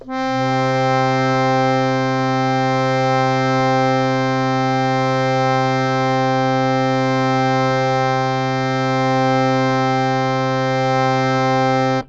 interactive-fretboard / samples / harmonium / C3.wav
C3.wav